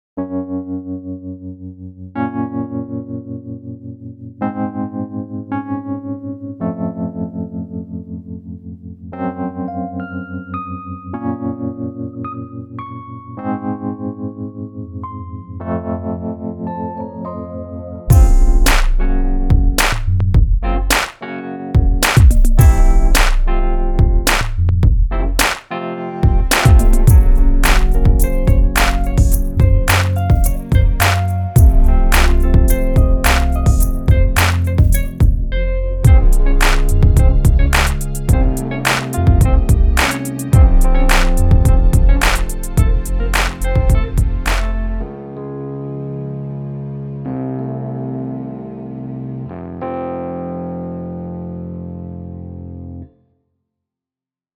兼具古典韵味与高通用性，采样了其原型的完整动态范围及音色特性。
前卫的电子钢琴
音质真实，风格多样
SCARBEE A-200可以驾驭从柔和、甜美到尽情演奏时的尖锐、失真等风格。